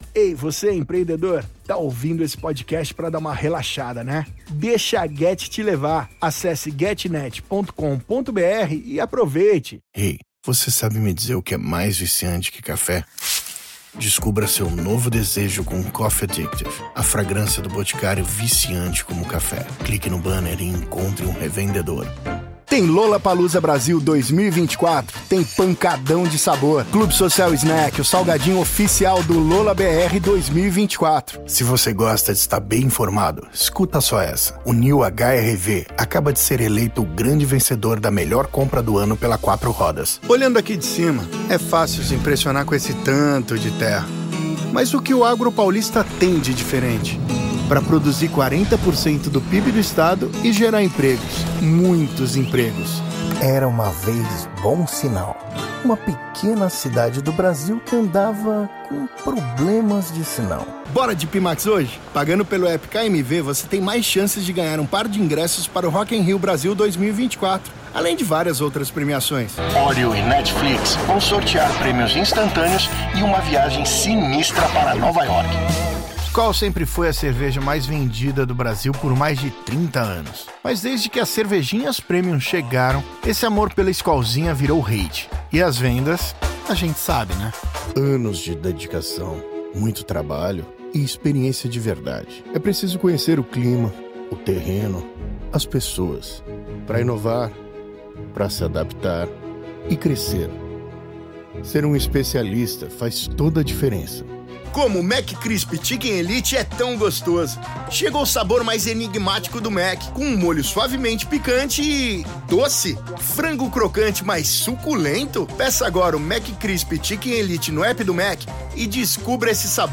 Zuversichtlich
Konversation